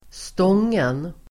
Uttal: [²st'ång:en]